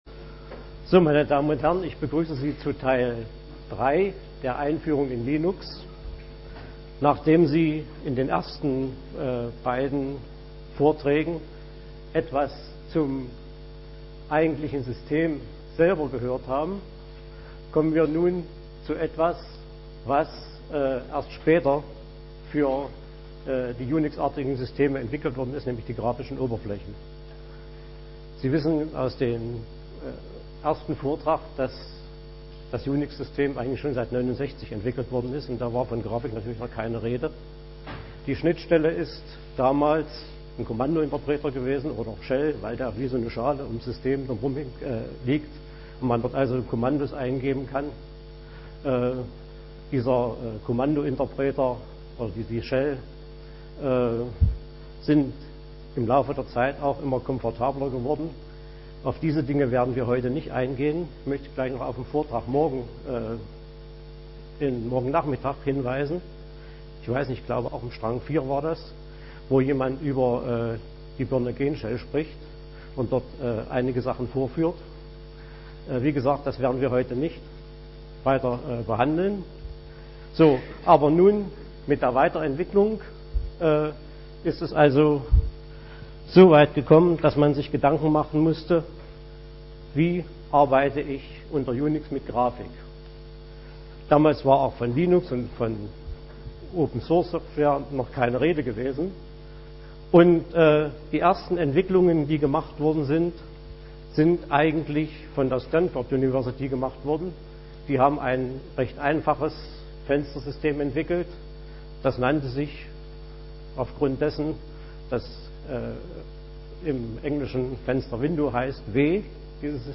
5. Chemnitzer Linux-Tag
Samstag, 12:00 Uhr im Raum V4 - Einführung